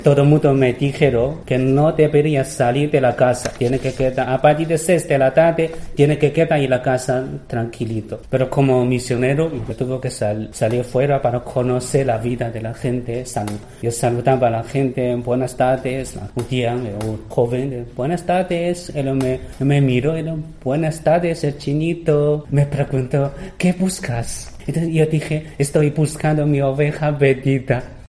Un sacerdote chino cuenta así su historia